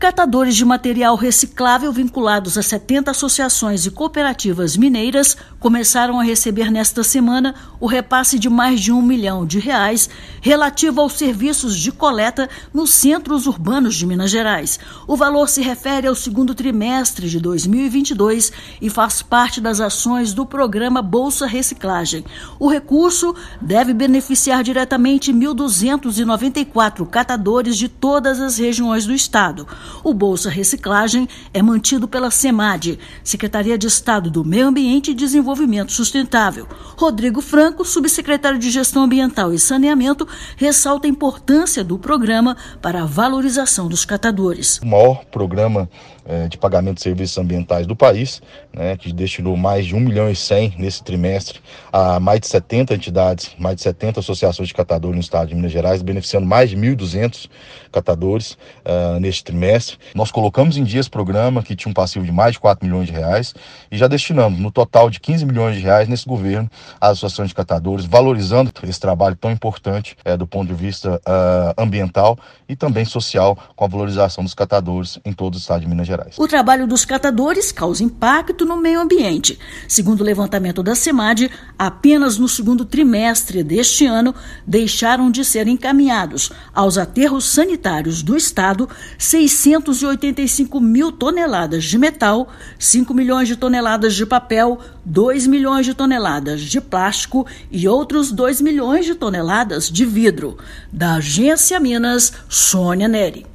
Recurso deve beneficiar diretamente 1.294 catadores de todas as regiões do estado. Ouça matéria de rádio.